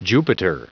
Prononciation du mot jupiter en anglais (fichier audio)
Prononciation du mot : jupiter